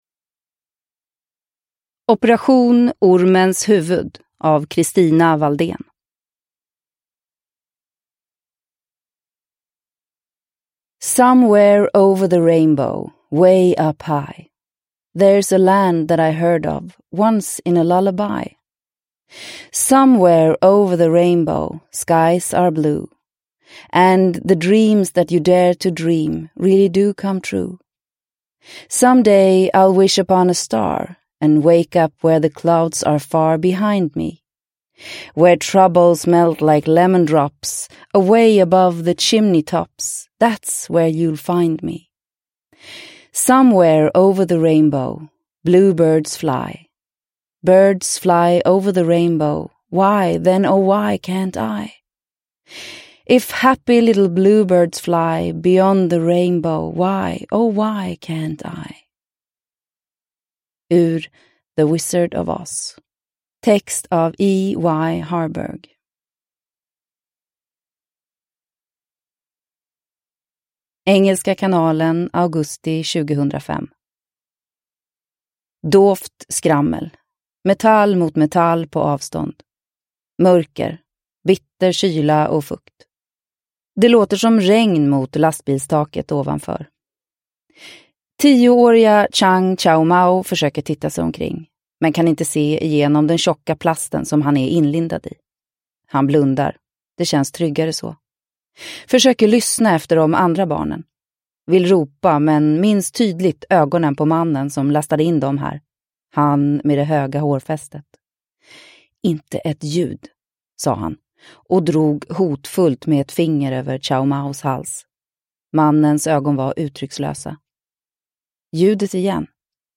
Operation Ormens huvud – Ljudbok – Laddas ner